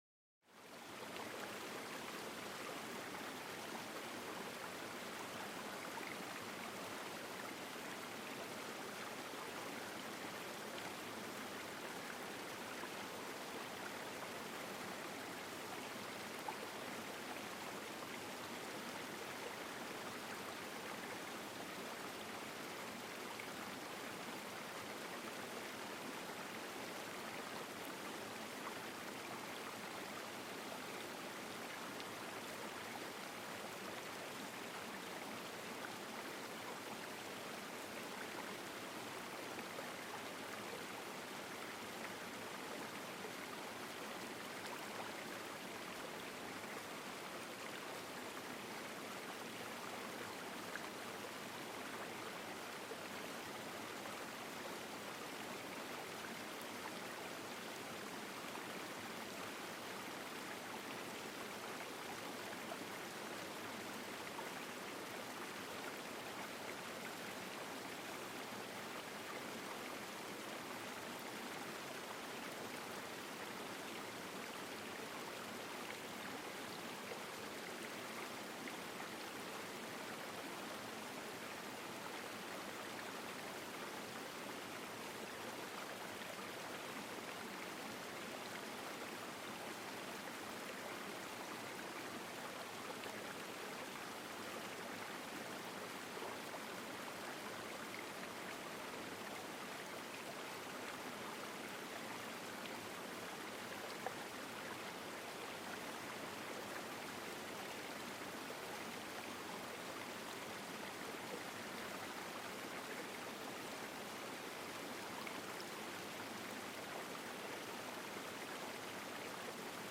SCHLAF & ENTSPANNUNGS-PERFEKTION: Bergbach-Morgenlicht mit perfekten Klängen
Naturgeräusche